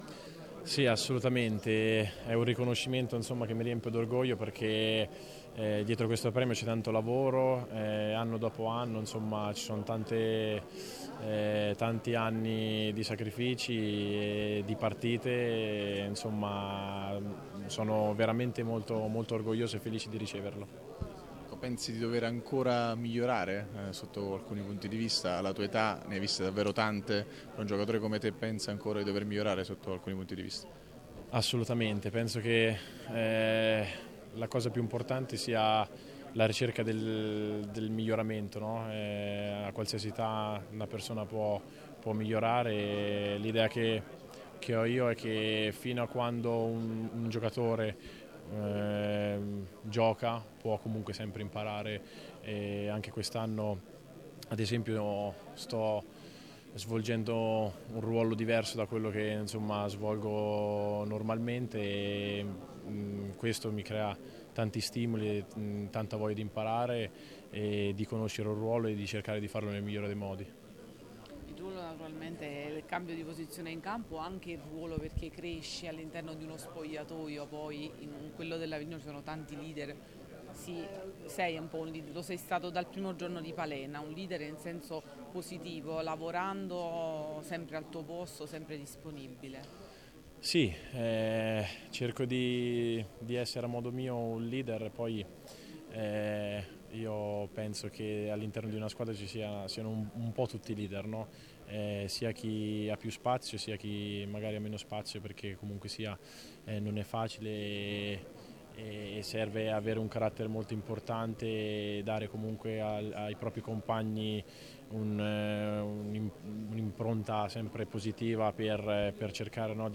Festival del Calcio 2023